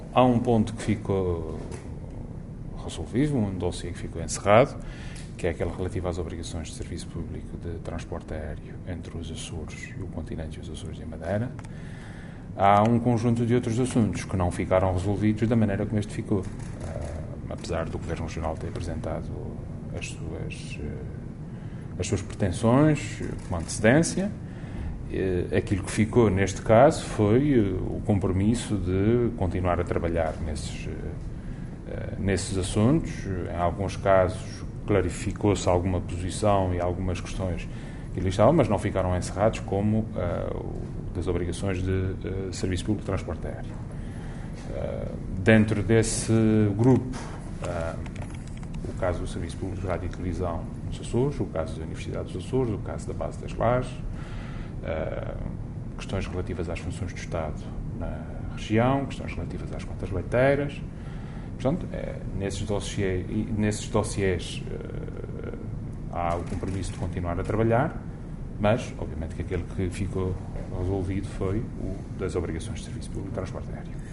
“Por essa razão, insistimos e consideramos ser fundamental avançar, desde já, com um Plano de Revitalização Económica que pudesse acudir a esta situação”, frisou Vasco Cordeiro, em declarações aos jornalistas depois de ter acompanhado o Primeiro Ministro numa visita aos Açores, que incluiu uma reunião com a Comissão Representativa dos Trabalhadores portugueses daquela base.